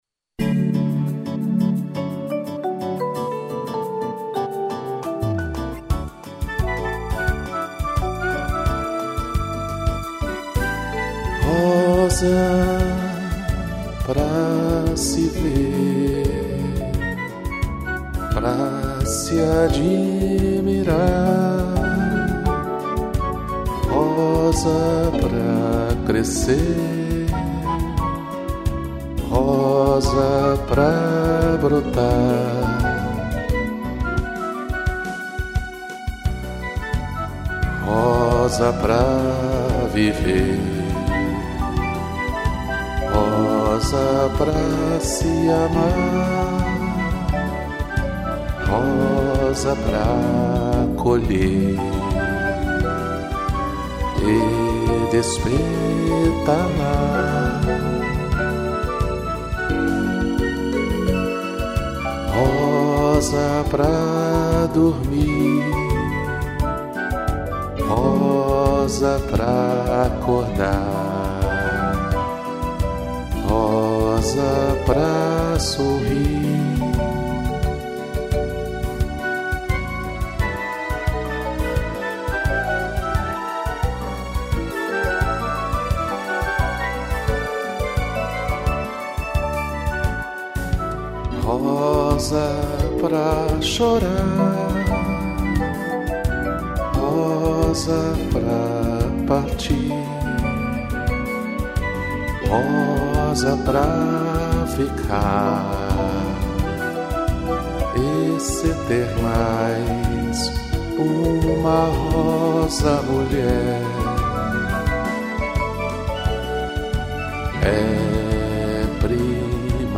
marimba, strings, flauta